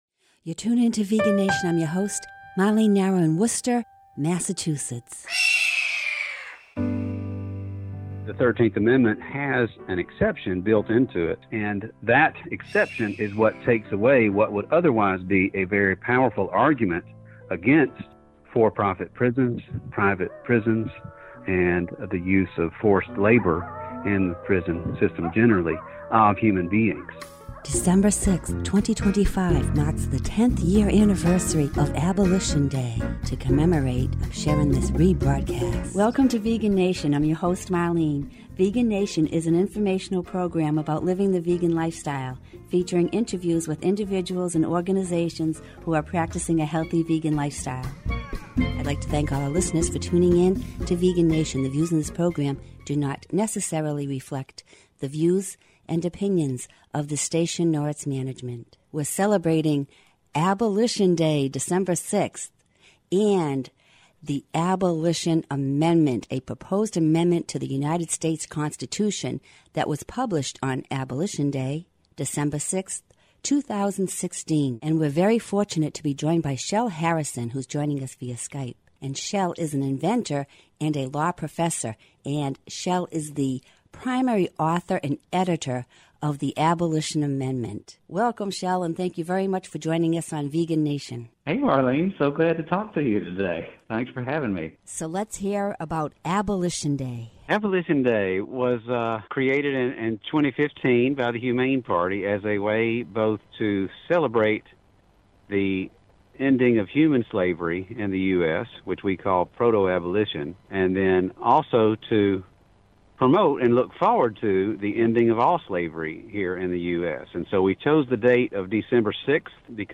Vegan Nation is a 1/2-hour radio show featuring vegan music, news, events and interviews.